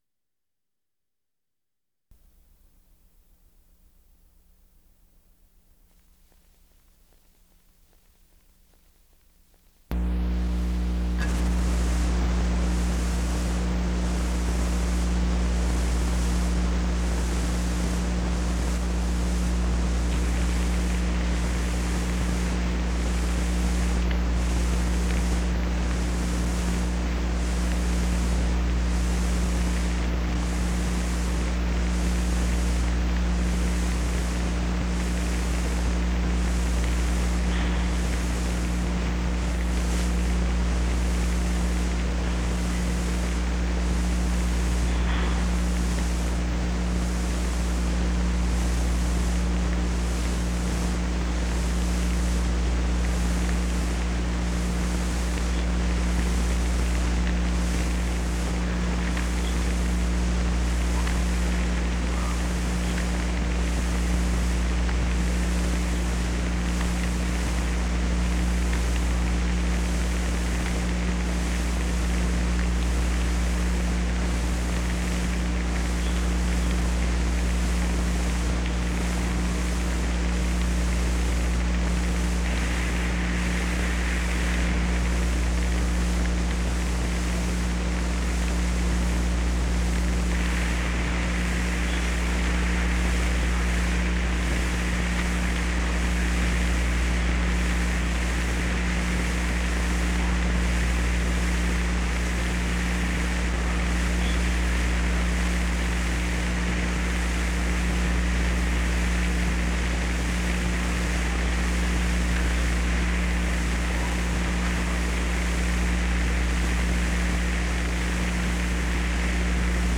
Secret White House Tapes | Harry S. Truman Presidency Recording 2, Part 1 Rewind 10 seconds Play/Pause Fast-forward 10 seconds 0:00 Download audio Previous Recording 1, Part 1 More Harry S. Truman Recordings View all Harry S. Truman tapes